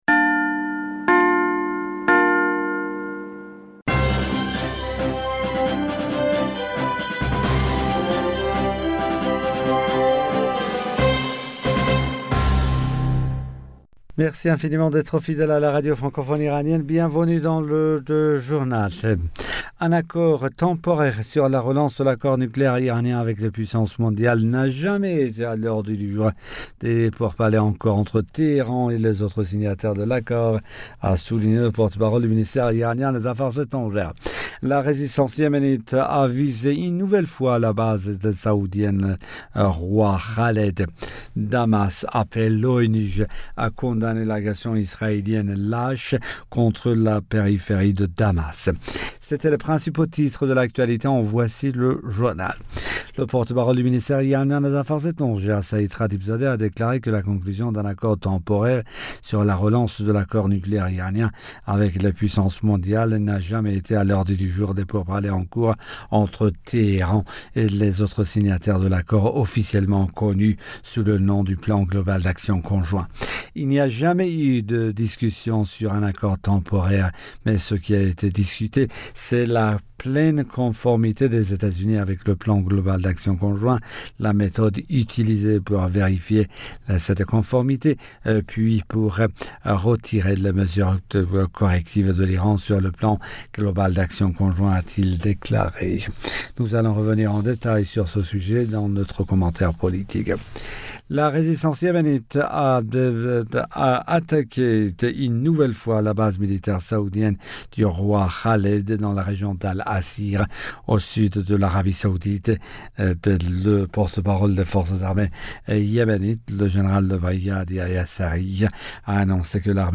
Bulletin d'information du 23 Avril 2021